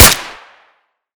sr1m_shoot_sil.ogg